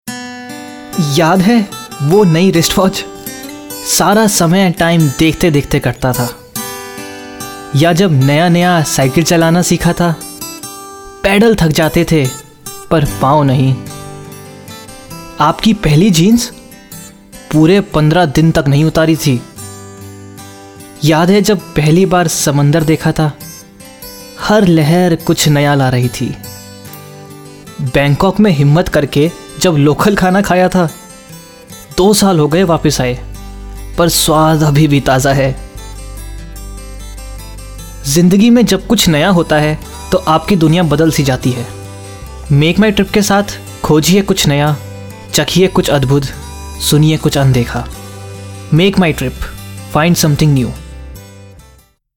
Medium Base, Smooth, Pleasant, Soft &#61558
My Dialect is Hindi-Urdu.
Sprechprobe: Werbung (Muttersprache):